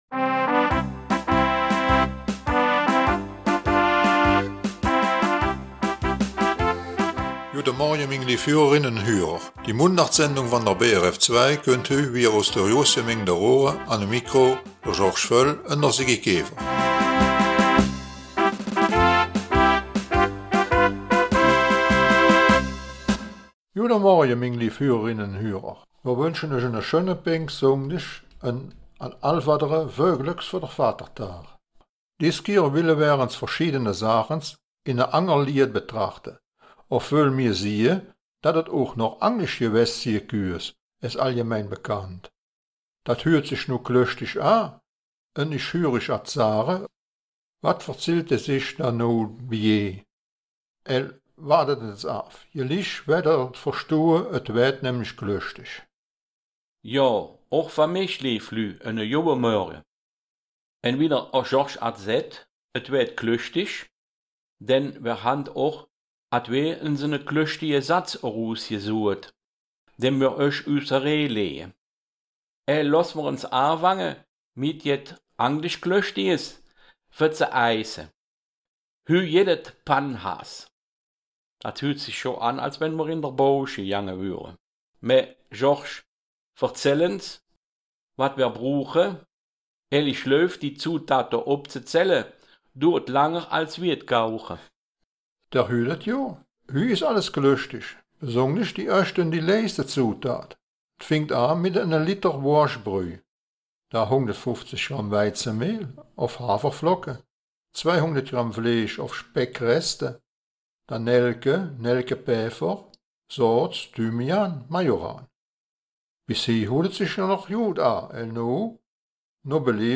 Raerener Mundart - 8. Juni